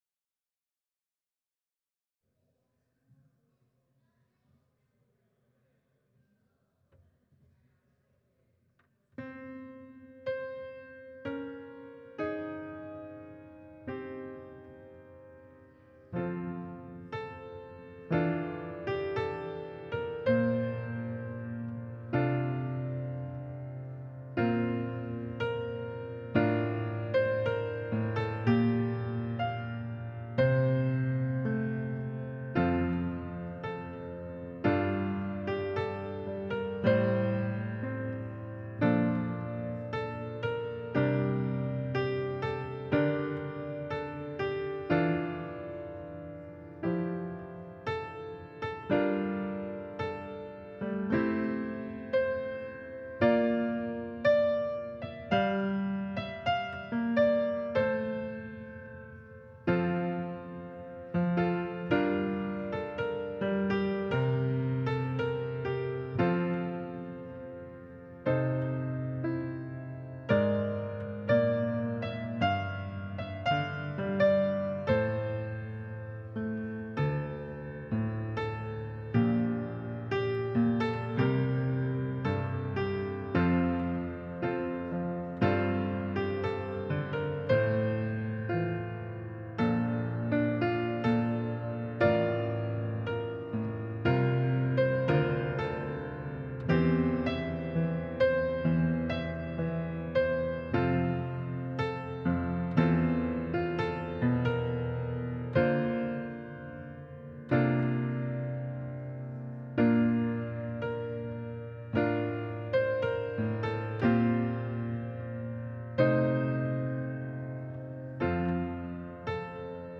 Passage: Luke 6: 17-31 Service Type: Sunday Service Scriptures and sermon from St. John’s Presbyterian Church on Sunday